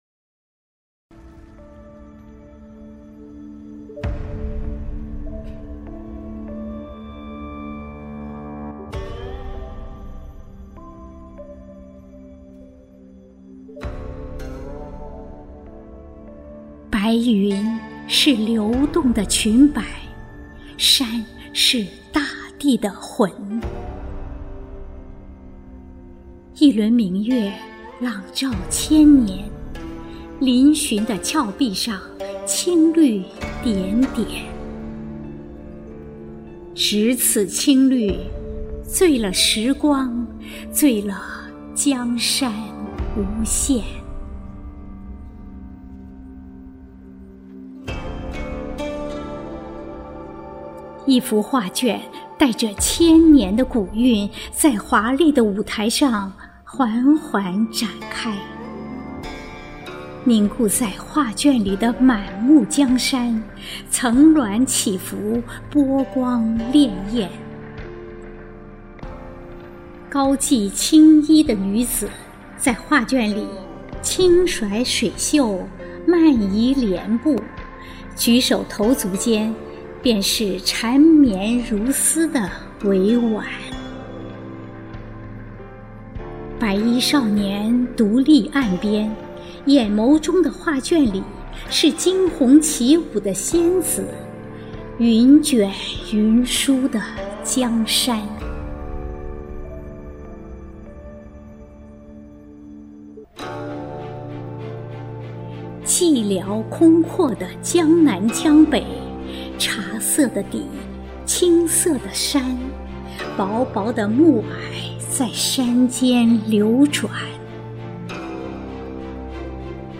图文并茂，久违的专业朗诵，声音清澈亲切，好！